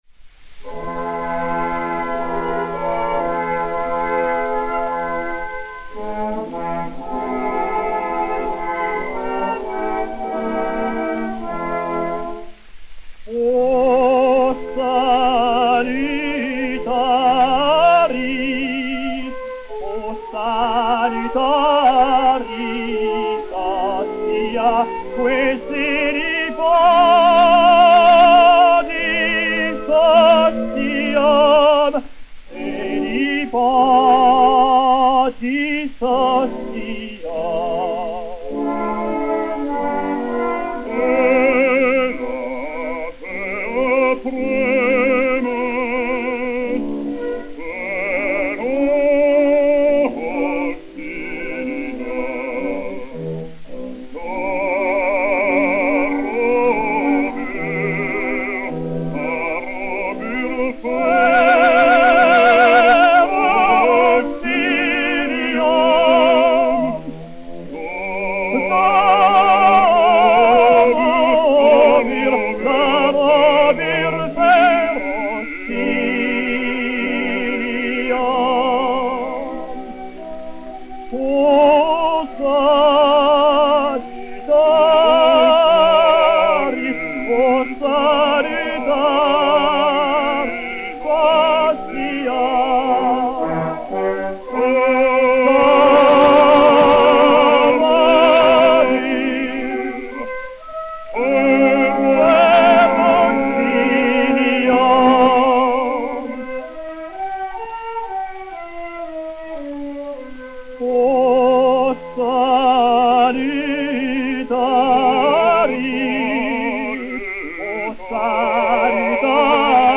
basse française
Orchestre